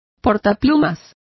Complete with pronunciation of the translation of penholder.